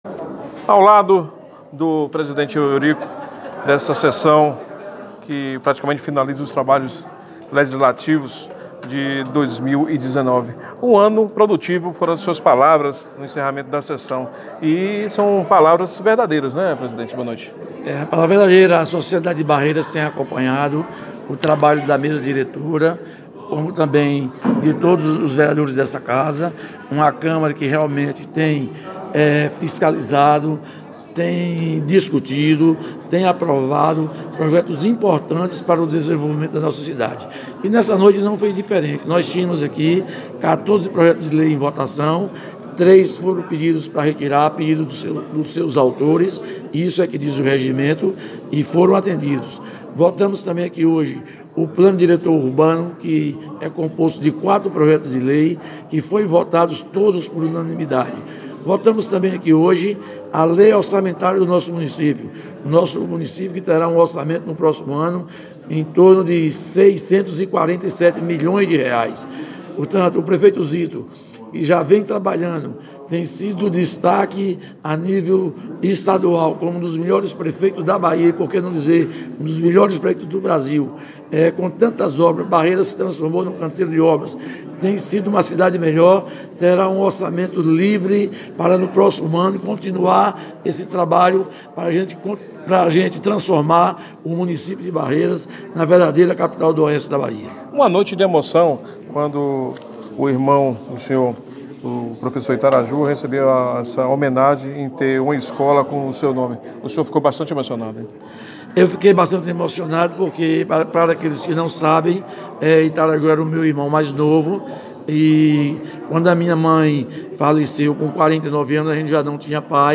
POD CAST do Site Mais Oeste – Entrevista com o Presidente da Câmara de Vereadores Eurico Queiroz que analisou vários assuntos dentre eles orçamento para 2020, o PDDU e a emoção de homenagem ao irmão , aperte o play: